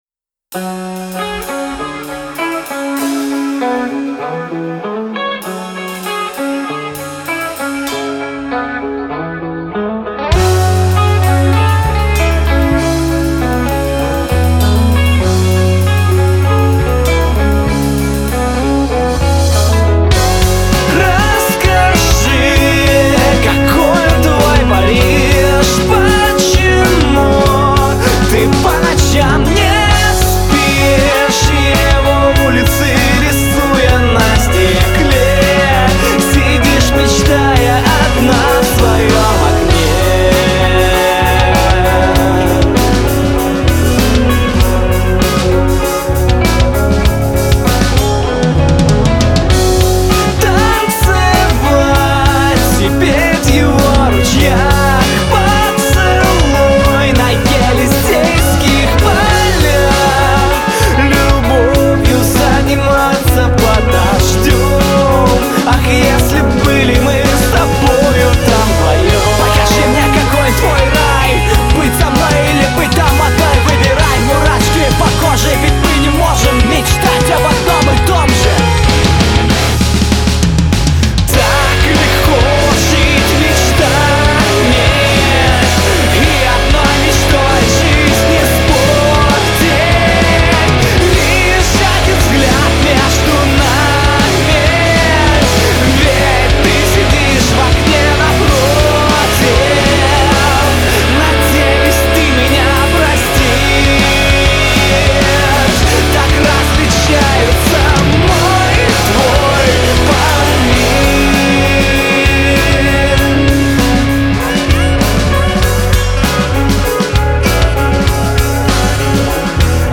Alt. Metal
sax